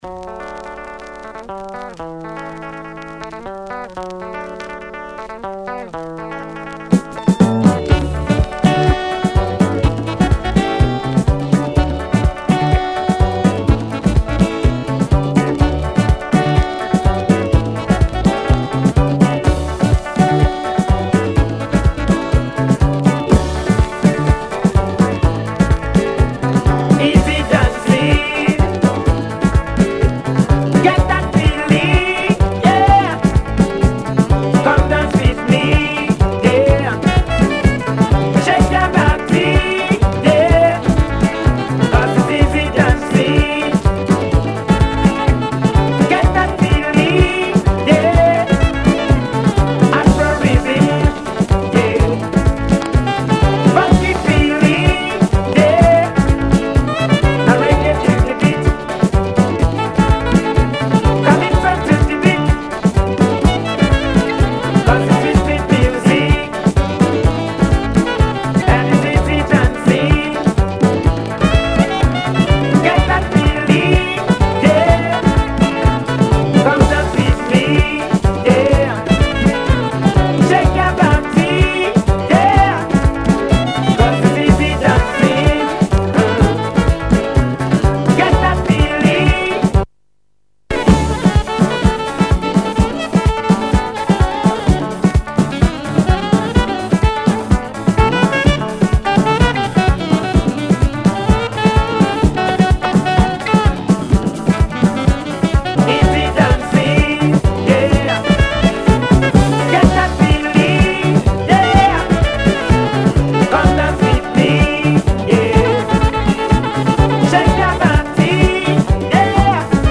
Caribbean inspired party number
Calypso number